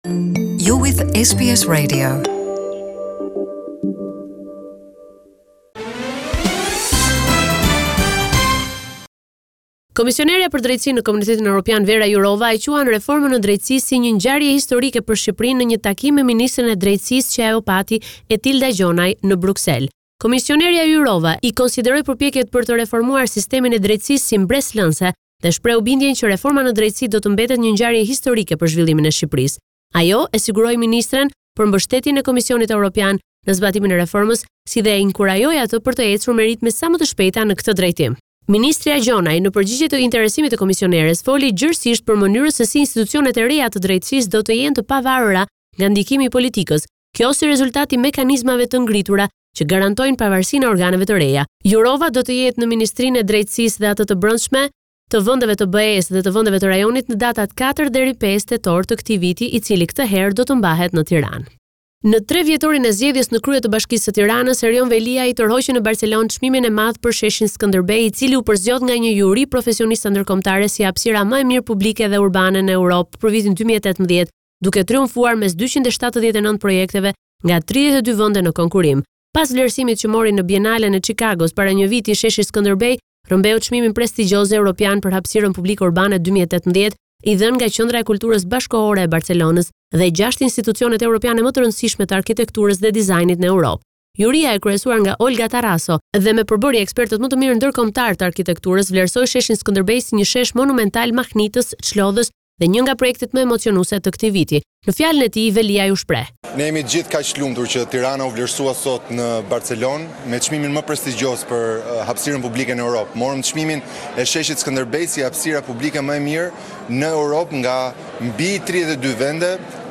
This report summarising the latest developments in news and current affairs in Albania.